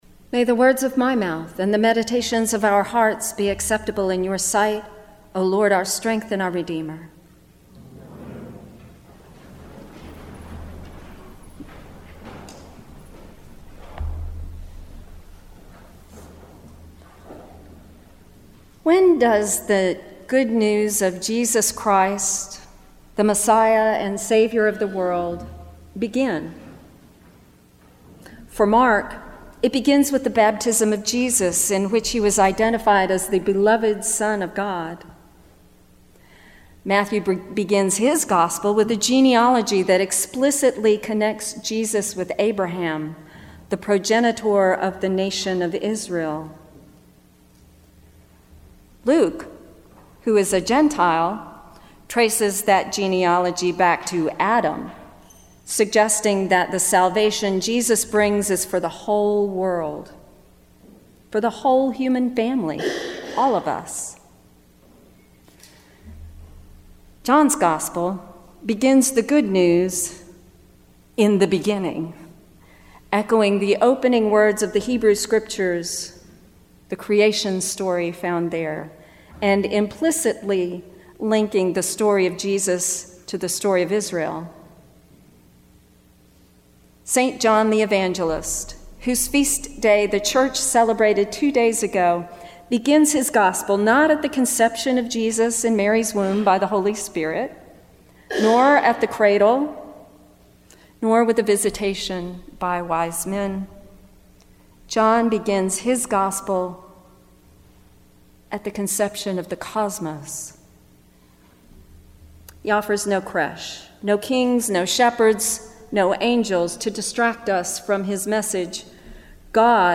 Homily
at the 10:30 am Holy Eucharist service.